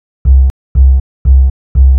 TSNRG2 Off Bass 010.wav